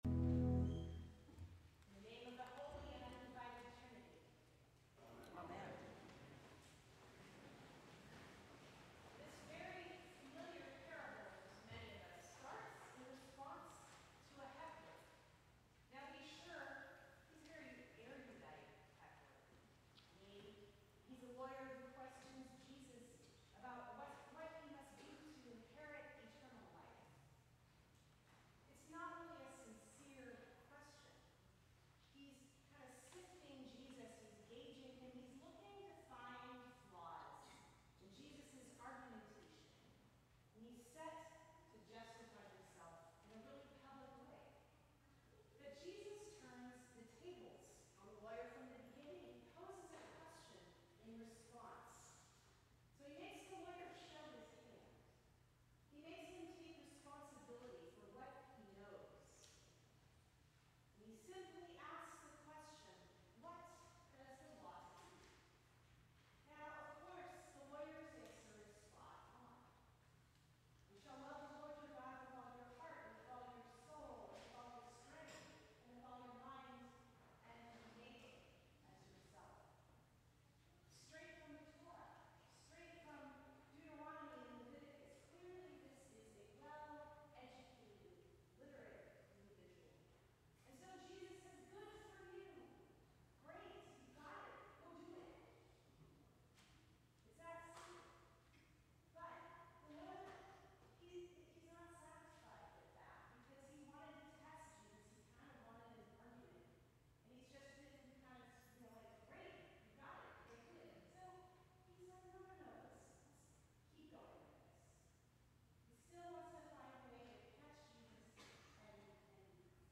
Sermons from St. Cross Episcopal Church Fifth Sunday after Pentecost Jul 13 2025 | 00:11:55 1x 00:00 / 00:11:55 Subscribe Share Apple Podcasts Spotify Overcast RSS Feed Share Link Embed